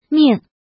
怎么读
mìng
ming4.mp3